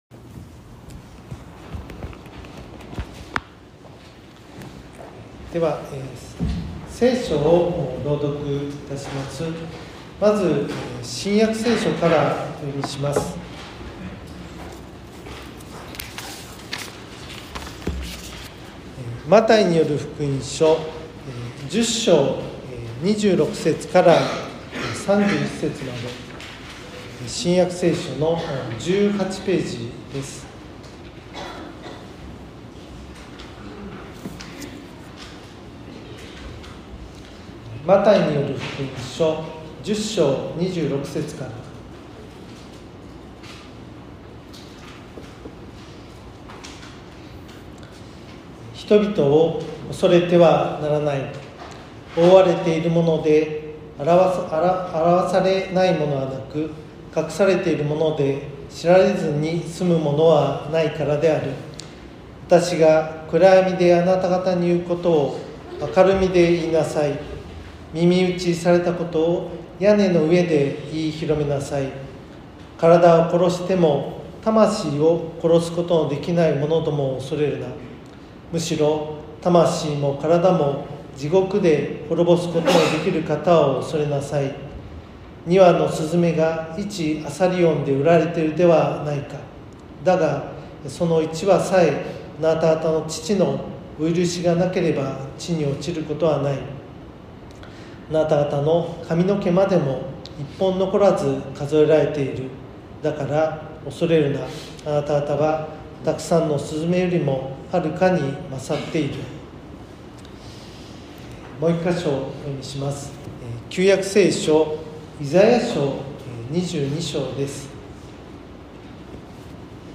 礼拝説教 日曜朝の礼拝
礼拝説教を録音した音声ファイルを公開しています。